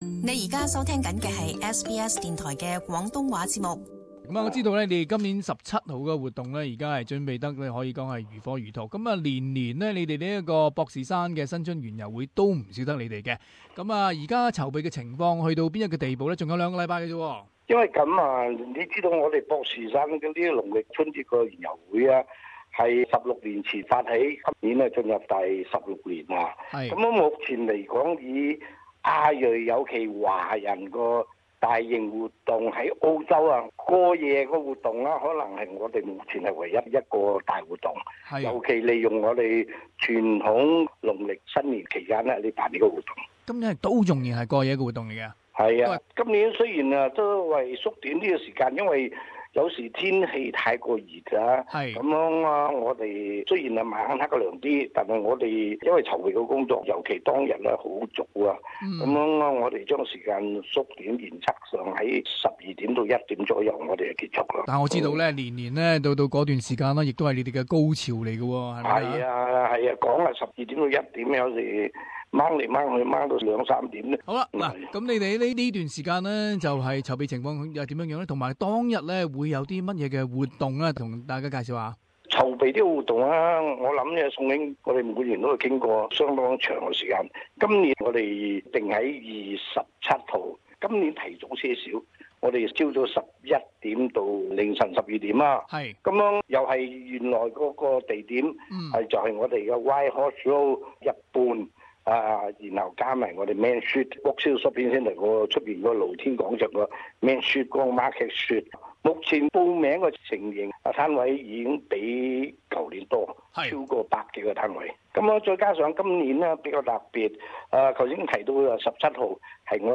【社團專訪】白馬商會博士山新春園遊會籌備進展